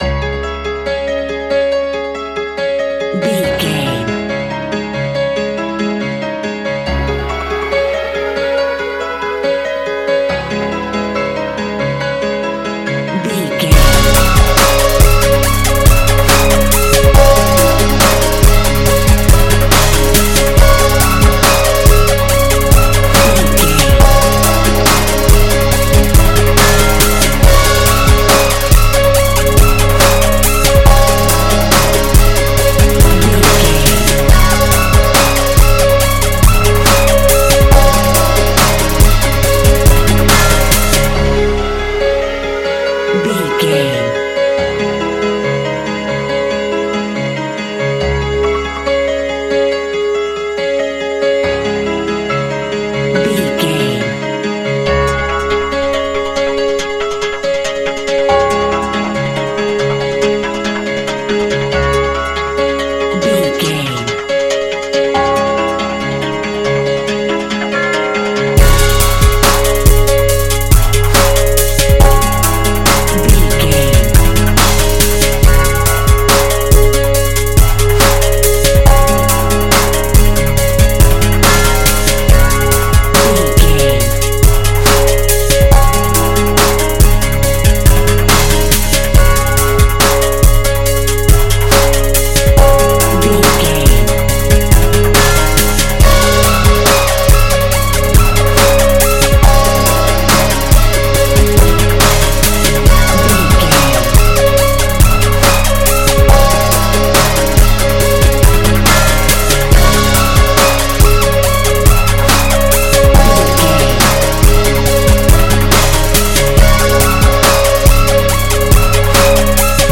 Modern Huge Dubstep Beat.
Epic / Action
Fast paced
Aeolian/Minor
C#
aggressive
powerful
dark
groovy
futuristic
driving
energetic
drum machine
synthesiser
piano
percussion
breakbeat
synth leads
synth bass